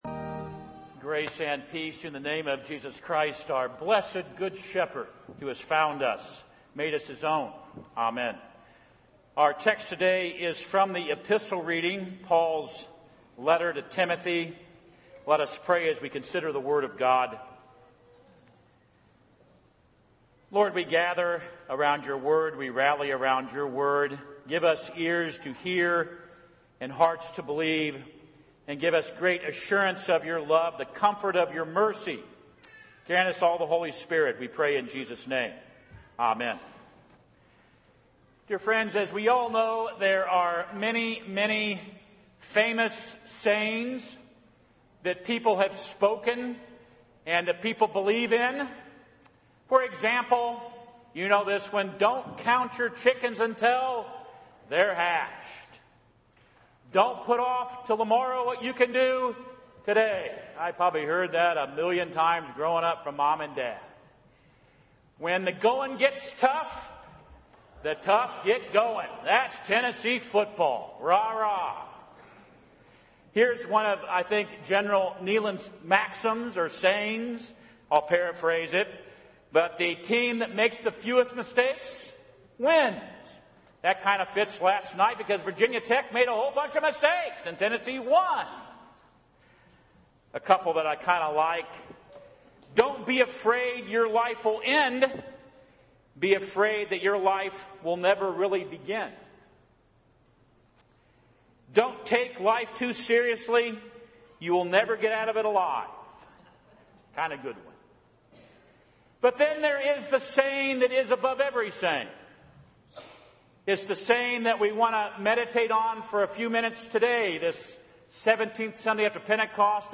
1 Timothy 1:12-17 Audio Sermon